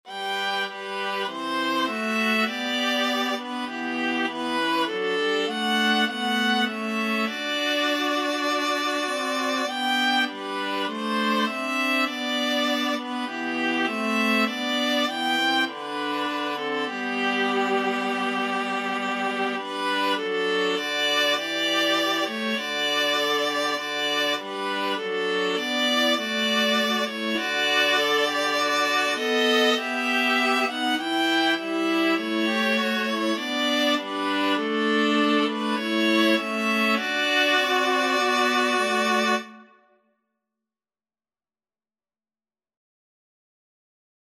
Christian Christian Viola Trio Sheet Music All Things Bright and Beautiful
Free Sheet music for Viola Trio
G major (Sounding Pitch) (View more G major Music for Viola Trio )
4/4 (View more 4/4 Music)
Classical (View more Classical Viola Trio Music)